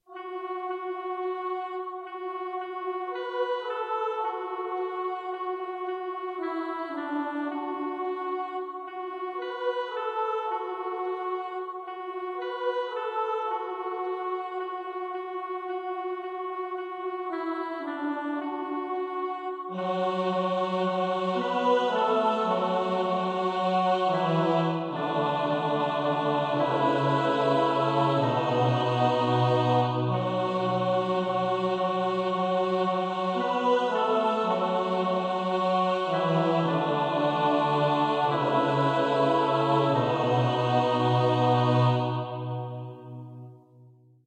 pro smíšený sbor